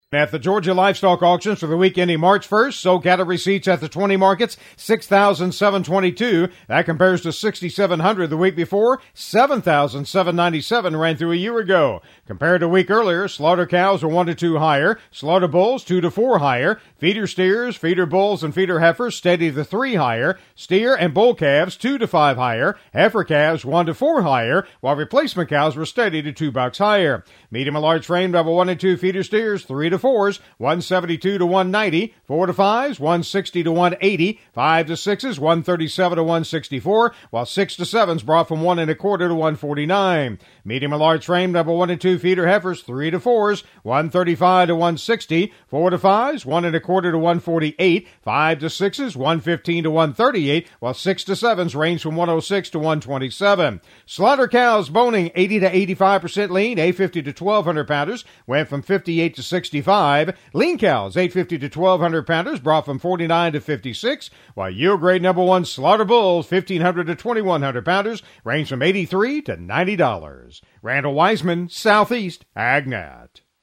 GA Livestock Market Report: